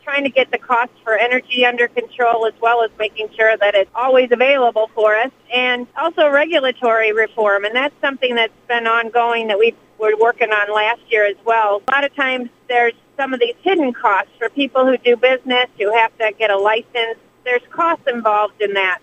During a recent interview with WLEN, State Representative Nancy Jenkins-Arno said residents continue raising concerns about affordability.